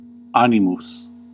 Ääntäminen accents without the "Hurry-furry" merger accents with the "Hurry-furry" merger: IPA : [ˈkɝ.ɹɪdʒ] UK : IPA : /ˈkʌɹ.ɪdʒ/ US : IPA : /ˈkʌɹ.ɪdʒ/ Tuntematon aksentti: IPA : /ˈkɝɪdʒ/ IPA : /ˈkʌrɪdʒ/